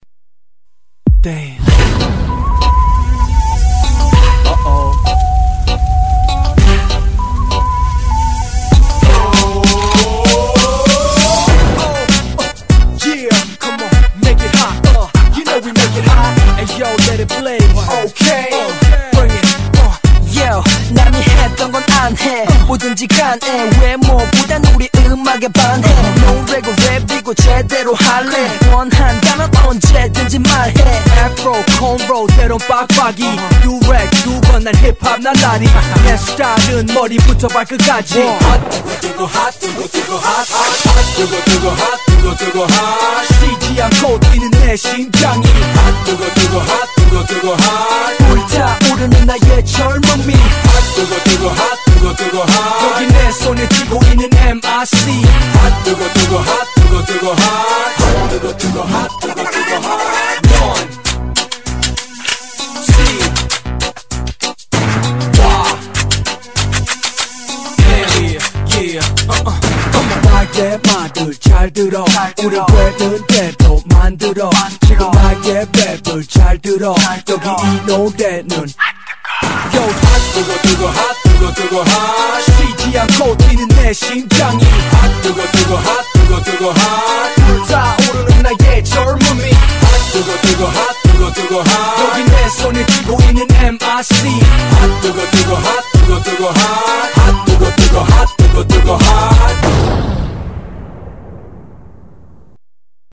BPM98--1
Audio QualityPerfect (High Quality)